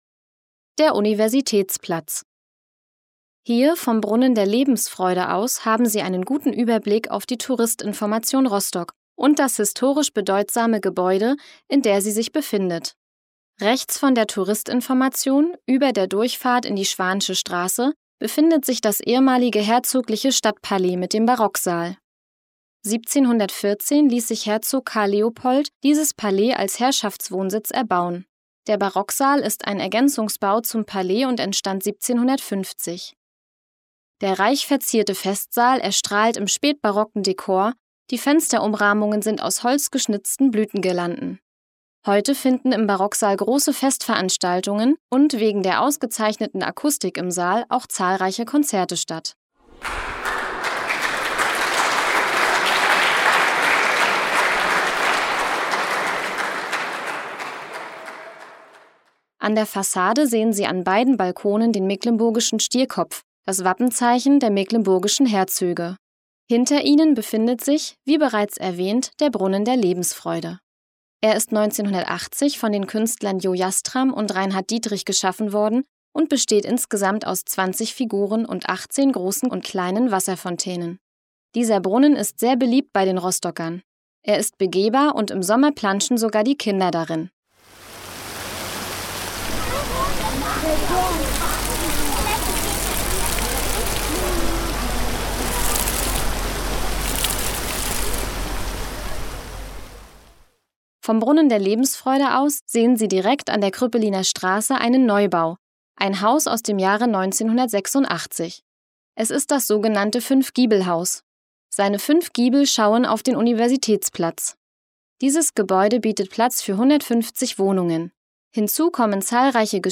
Audioguide Rostock - Station 2: Universitätsplatz